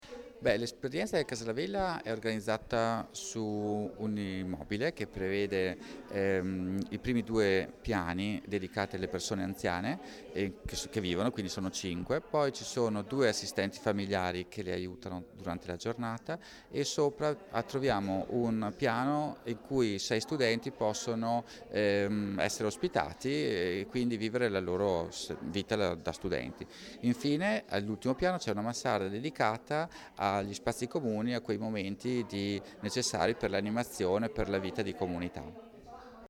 Riprese, immagini e interviste a cura dell'Ufficio Stampa -